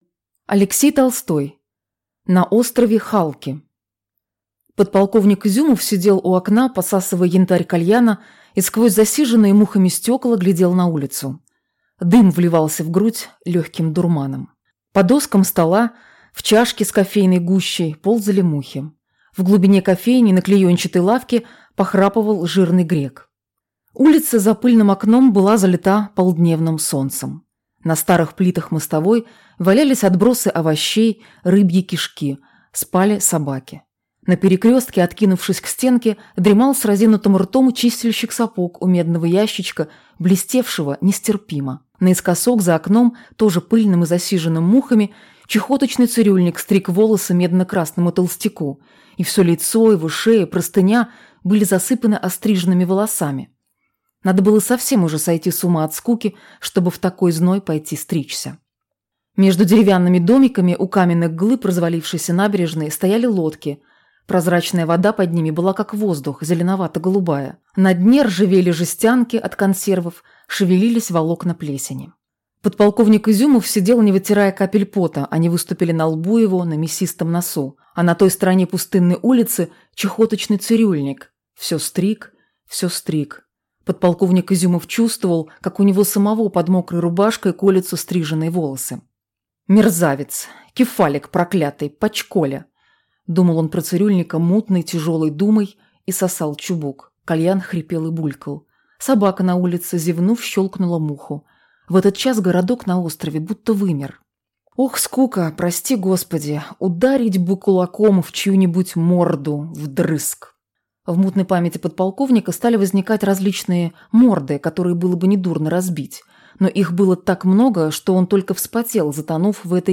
Аудиокнига На острове Халки | Библиотека аудиокниг